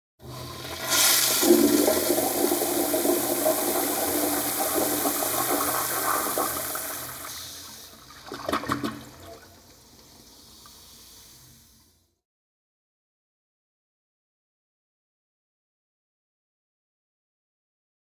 Traditionelle Spülung Standardspülung Moderne Spülung